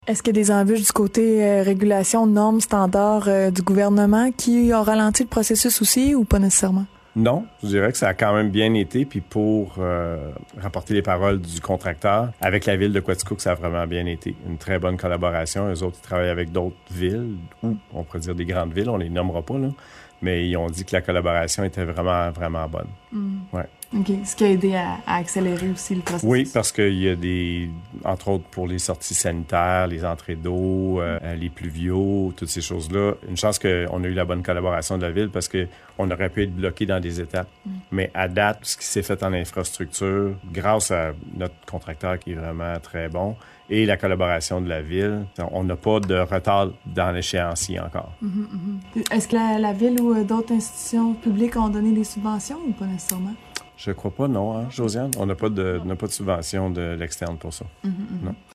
ENTREVUE-2.4.3-CLINIQUE-VET_01.mp3